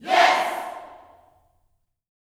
YES  07.wav